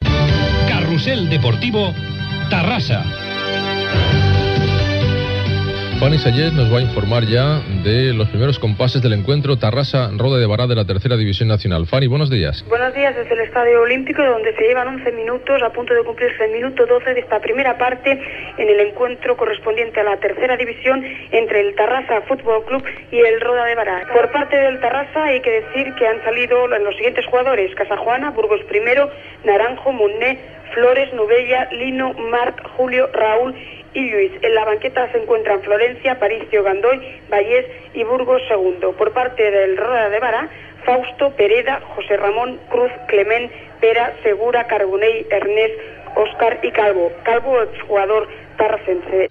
Indicatiu del programa, connexió amb l'estadi olímpic on es juga el partit de futbol masculí Terrassa Club de Futbol Roda de Berà: aliniació dels equips
Esportiu